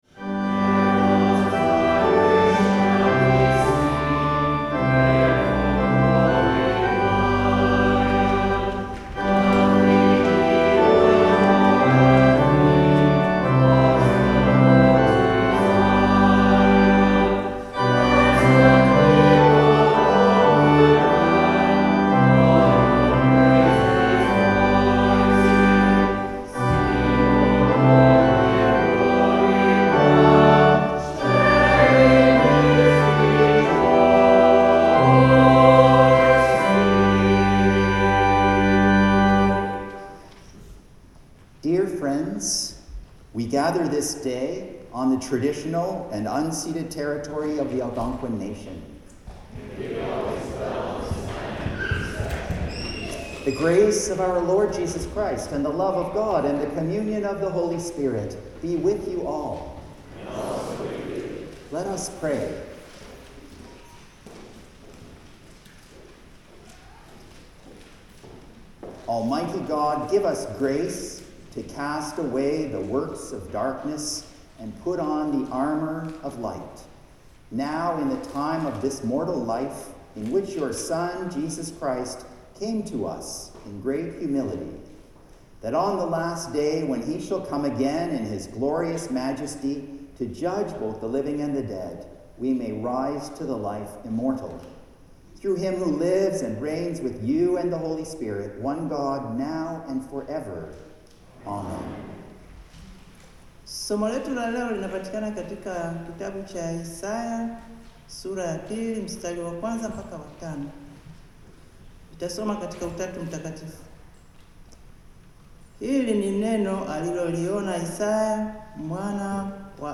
Hymn 109: When the King Shall Come Again (final verse)
The Lord’s Prayer (sung)
Hymn 114: Lo, He Comes With Clouds Descending